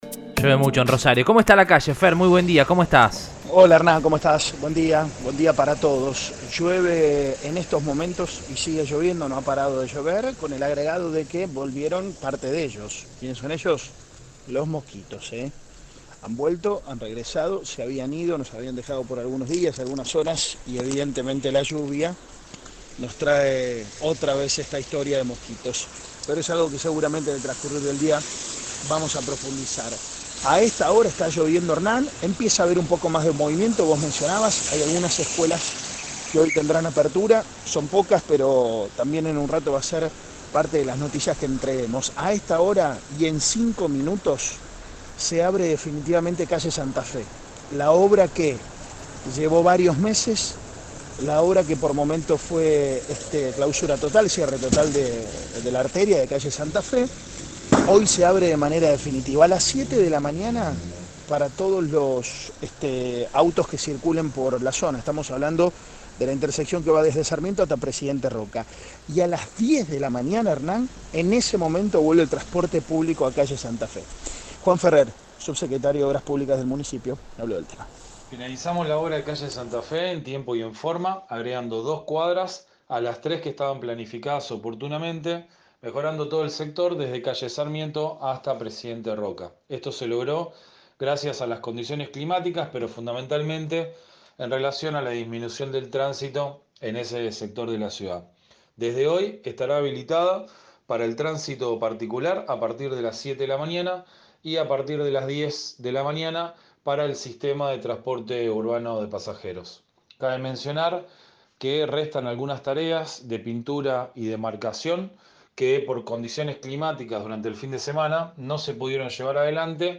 “Finalizamos la obra en tiempo y forma, agregando dos cuadras a las tres planificadas. Se logró gracias a las condiciones meteorológicas pero también a la reducción de tránsito”, dijo Juan Manuel Ferrer, subsecretario de Obras Públicas, al móvil de Cadena 3.